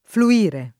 flu-&re] v.; fluisco [